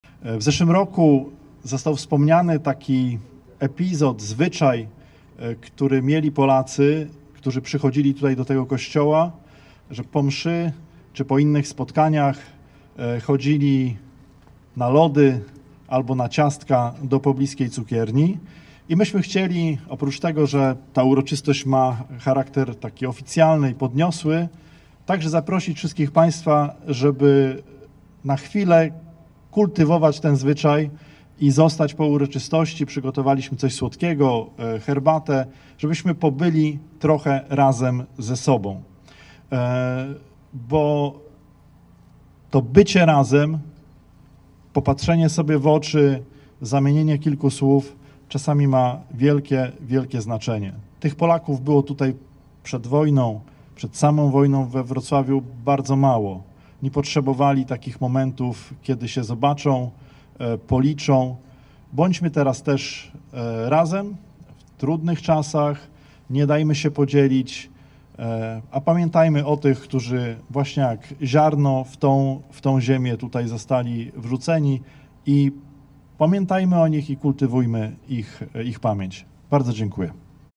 Uroczystości miały miejsce przy kościele św. Marcina na Ostrowie Tumskim we Wrocławiu.
W czasie uroczystości głos zabrali: